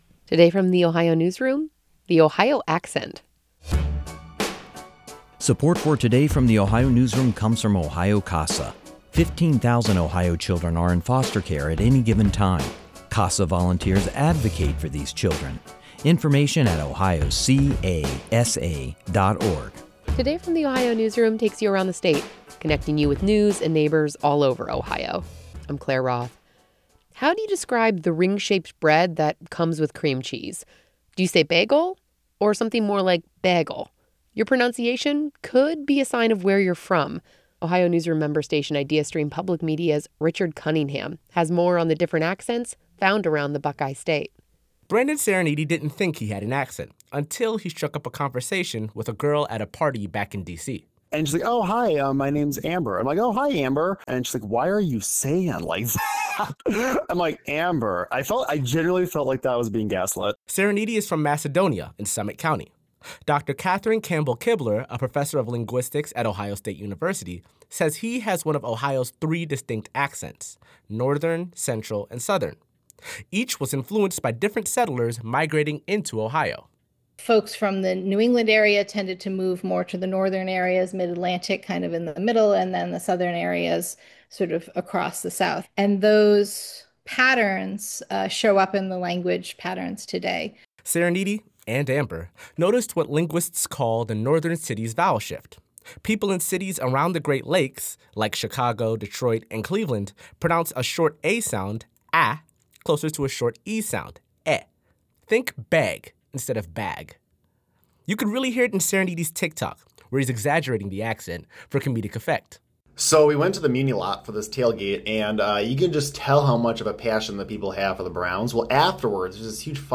Ohio's three regional accents
For example, a short 'o' sound might sound closer to a short 'a' sound – so "lot" would sound like 'laht.'